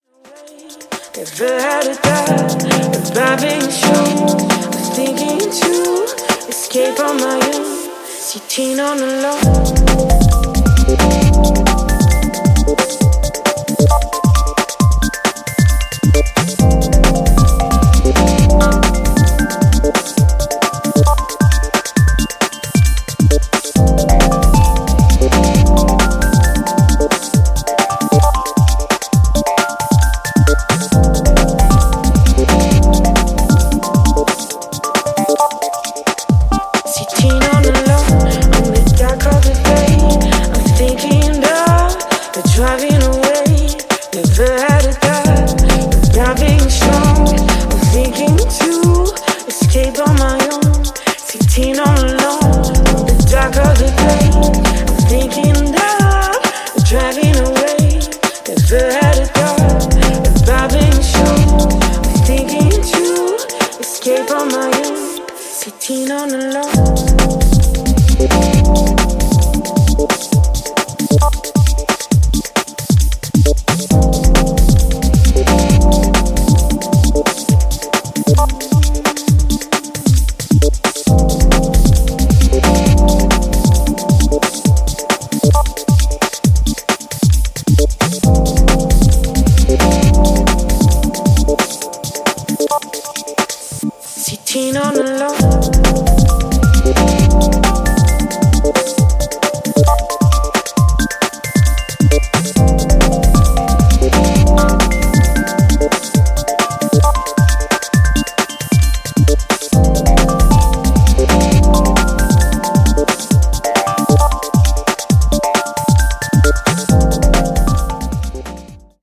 House Bass